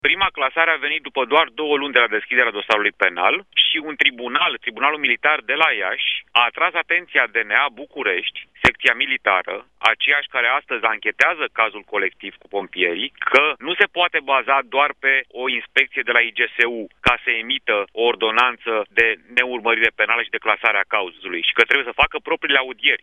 Redactorul șef al GSP – Cătălin Tolontan.
insert-1-tolontan.mp3